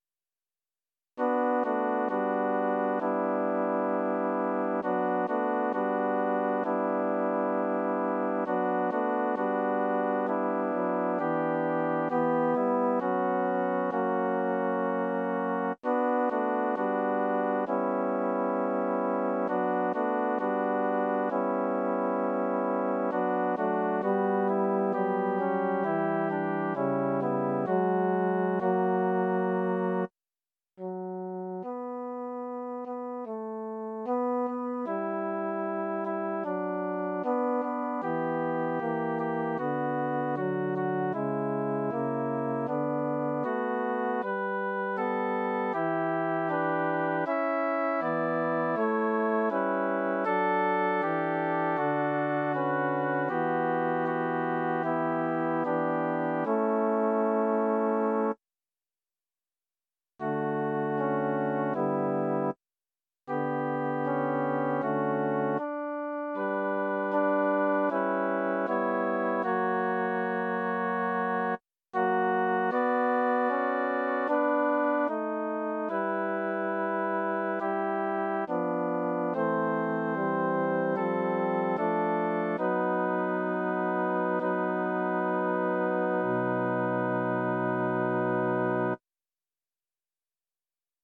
Title: Caro Mea Composer: Giudoco Traghi Lyricist: Number of voices: 4vv Voicing: SATB Genre: Sacred, Motet
Language: Latin Instruments: A cappella